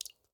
drip_water13.ogg